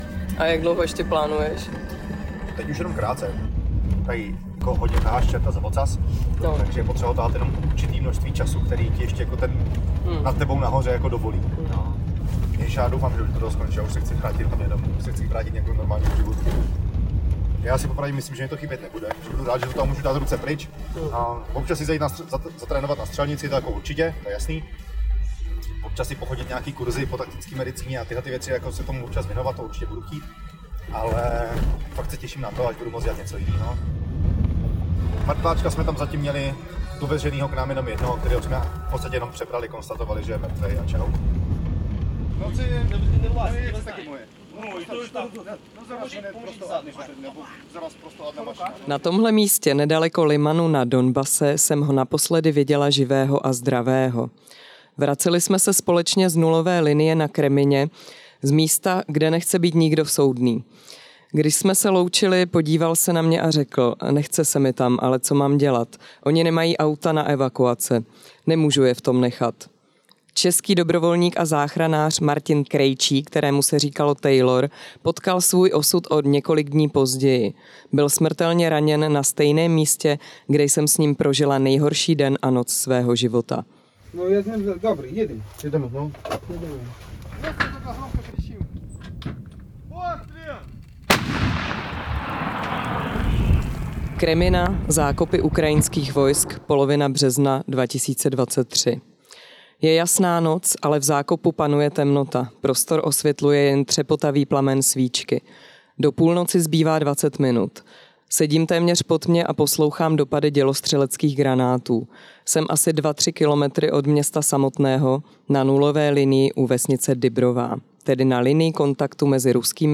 audioreportáži